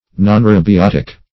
Search Result for " nonaerobiotic" : The Collaborative International Dictionary of English v.0.48: Nonaerobiotic \Non*a`er*o*bi*ot"ic\, a. (Biol.) Capable of living without atmospheric oxygen; anaerobic.
nonaerobiotic.mp3